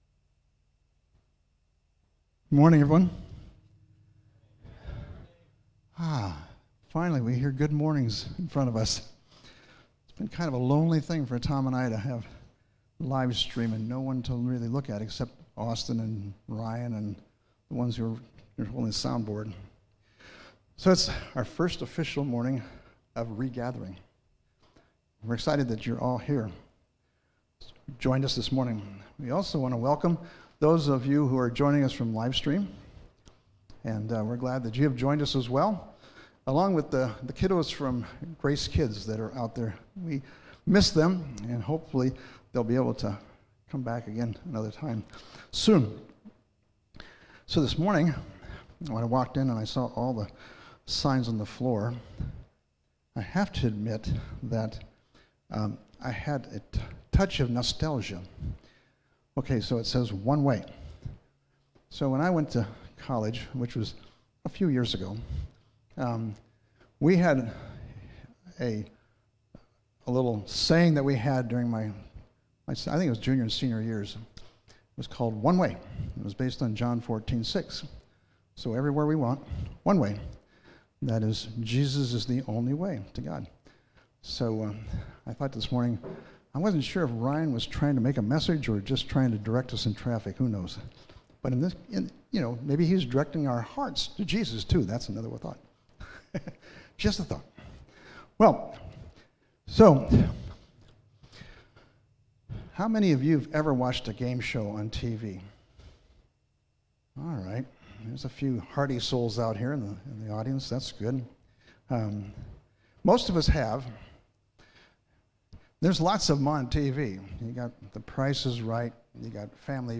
Jul 12, 2020 Truth or Consequences (07.12.2020) MP3 PDF SUBSCRIBE on iTunes(Podcast) Notes Discussion Sermons in this Series The greatest joy we could ever find is to know Christ fully. Paul shares that growing in the true knowledge of Jesus Christ will eclipse any and all life experiences.